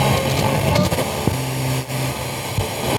80BPM RAD0-L.wav